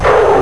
touchdown.wav